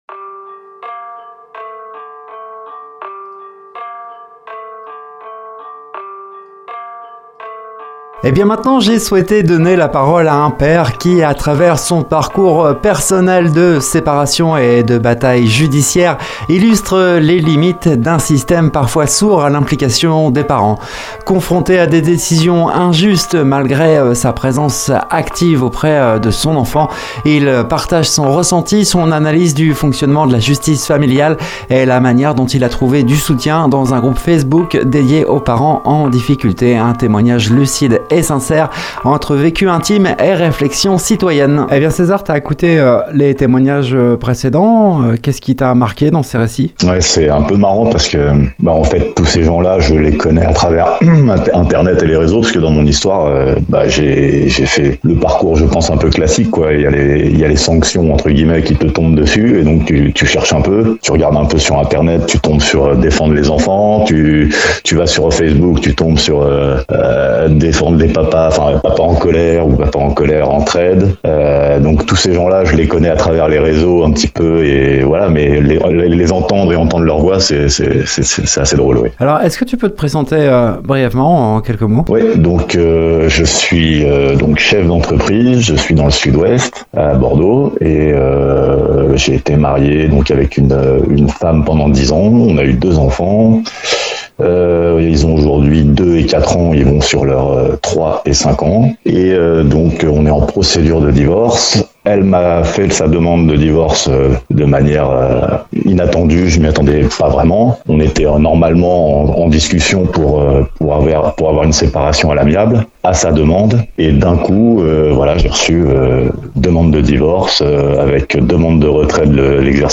Témoignage d’un père en lutte du 22.05.2025